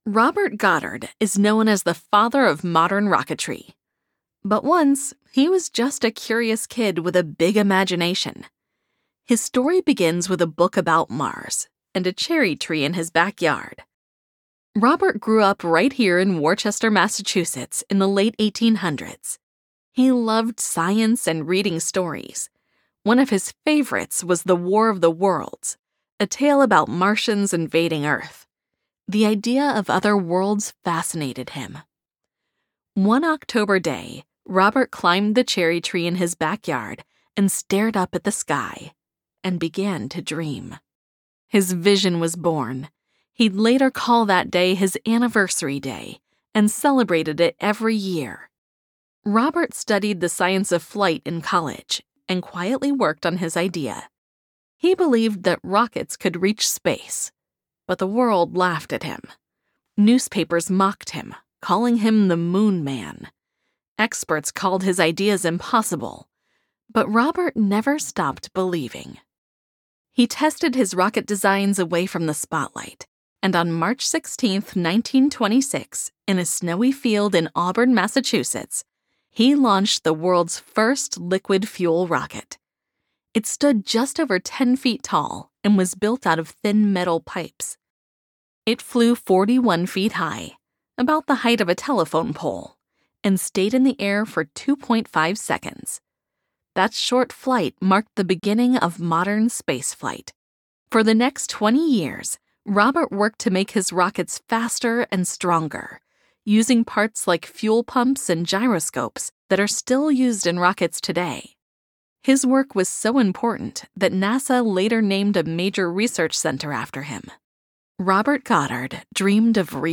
Female
English (North American)
Yng Adult (18-29), Adult (30-50)
Tour Guide
Museum Narration Sample
0630Museum_Narration.mp3